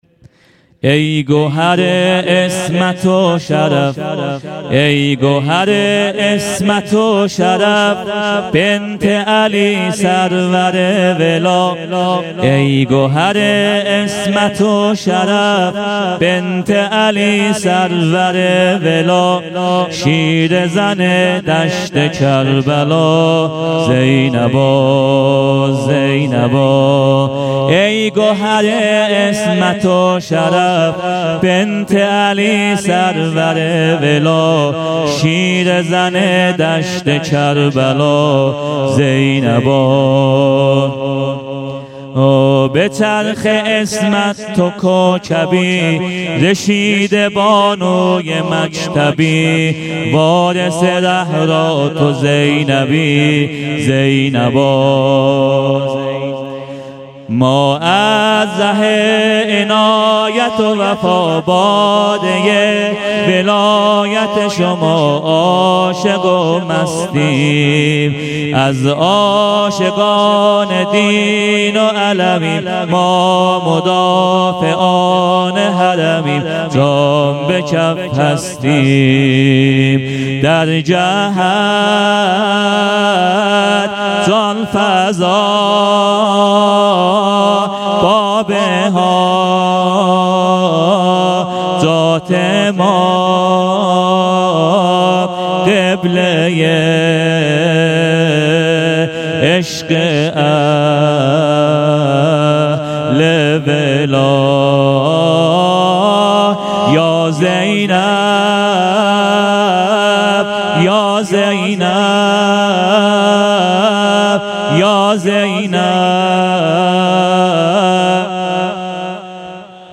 شب پنجم محرم 96
سينه زني